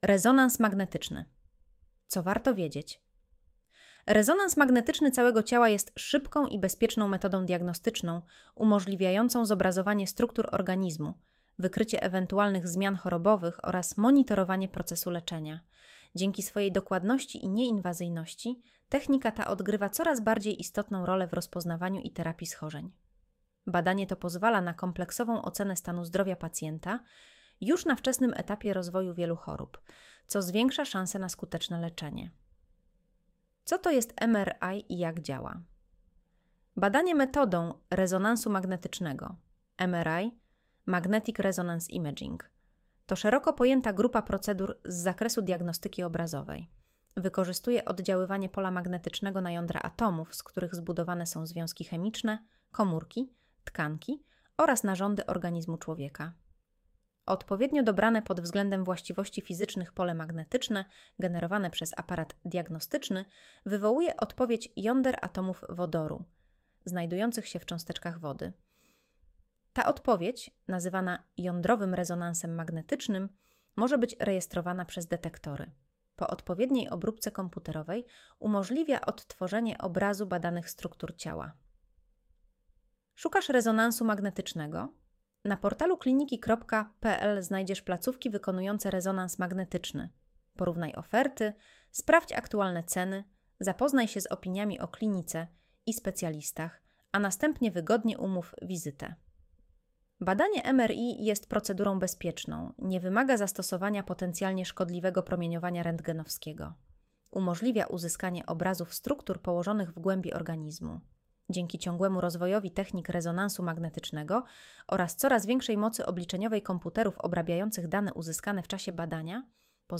Posłuchaj (09:43 min) Streść artykuł Słuchaj artykułu Audio wygenerowane przez AI, może zawierać błędy 00:00 / 0:00 Streszczenie artykułu (AI): Streszczenie wygenerowane przez AI, może zawierać błędy Spis treści Co to jest MRI i jak działa?